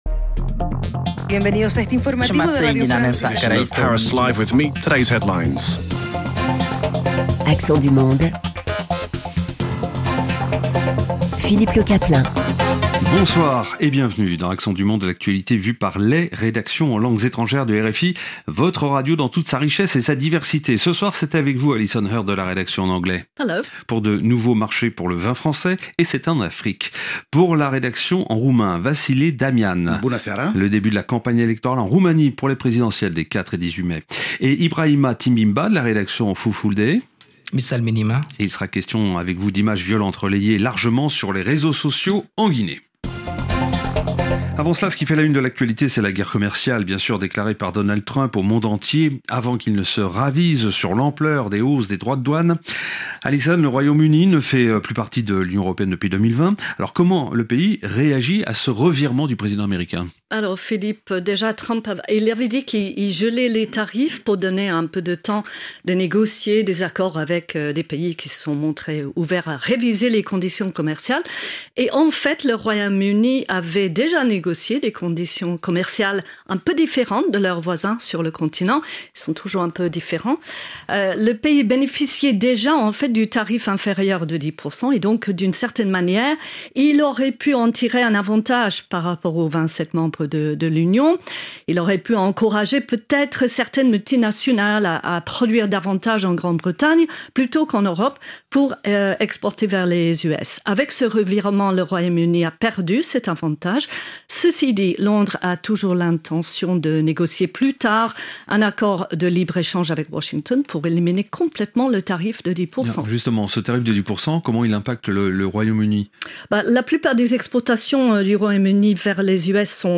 Le vendredi soir, dans Accents du monde, les journalistes des rédactions en langues étrangères croisent leurs regards, en français, sur l’actualité internationale et partagent les événements et les faits de société de leur région.